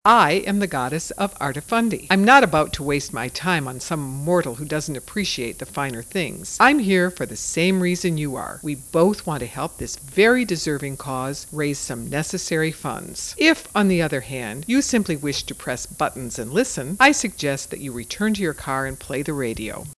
Goddess of Arta Fundi (430 mp3  or RealAudio 43k ),  an enthusiastic docent who suffers no fools and Thalia, the mischievous child Muse of Comedy.
The dialogue is full of asides, quotes, poems, and sound effects as it coaxes, wheedles, challenges, reasons, denigrates, seduces, and whines attempting to increase the visitor's interaction and monetary contributions.